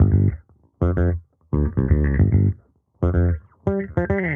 Index of /musicradar/sampled-funk-soul-samples/110bpm/Bass
SSF_JBassProc1_110G.wav